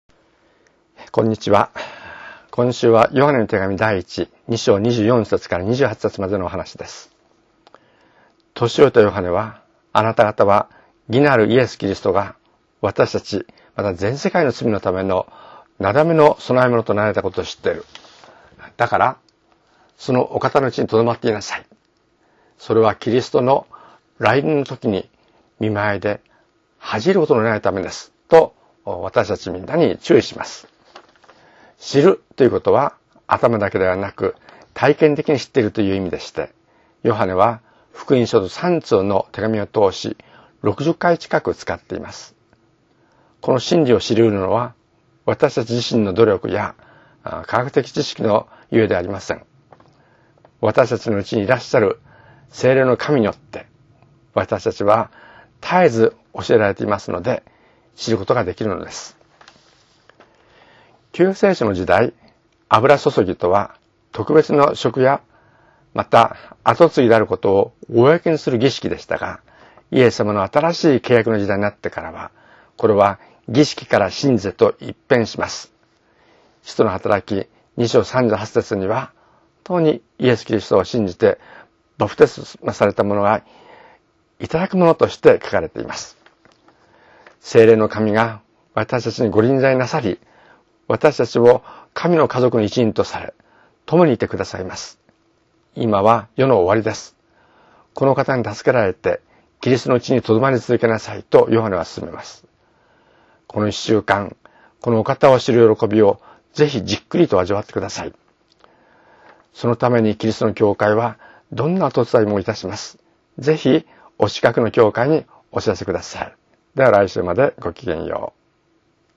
声のメッセージ